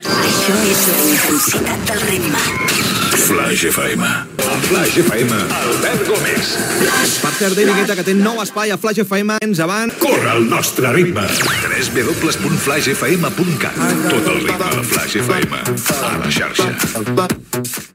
Identificació de la ràdio i del locutor, adreça web